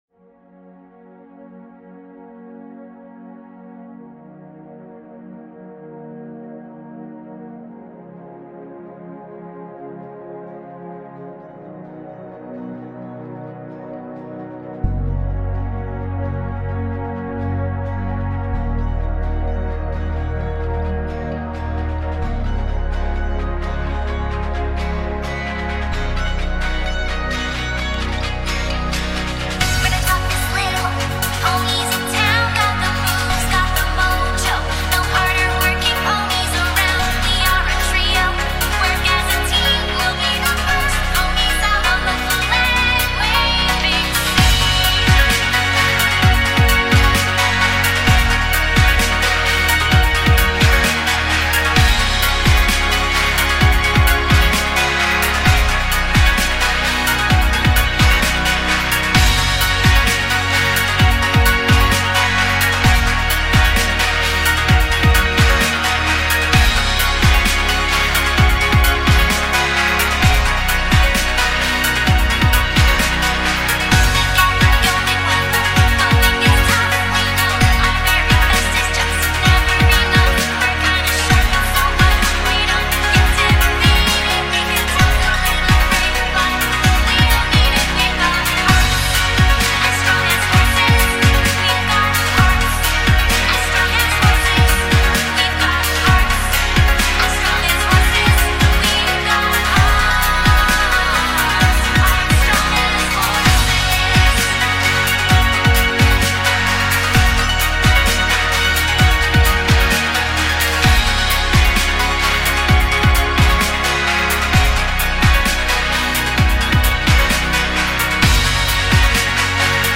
All tracks have better mixing now.